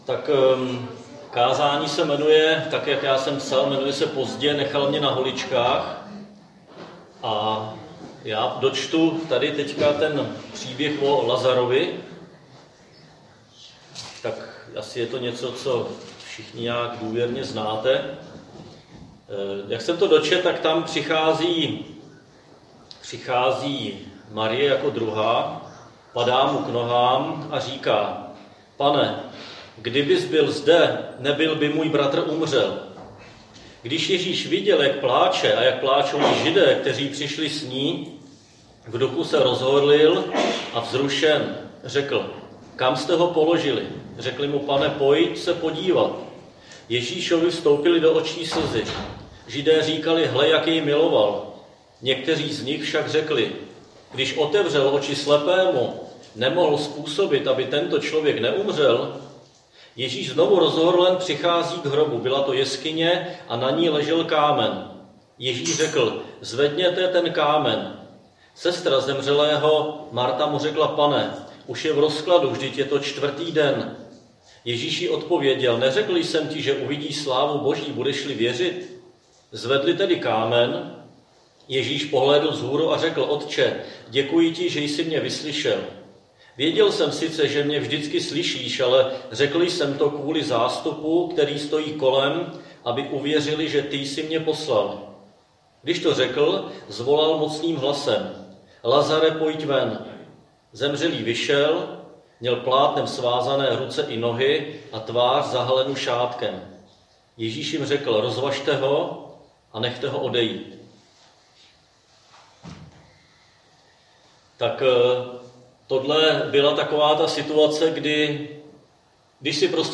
Křesťanské společenství Jičín - Kázání 7.11.2021